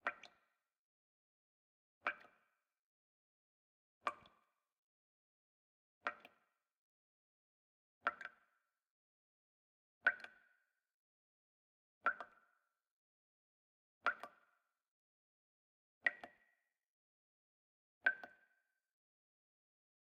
faucet_distant.ogg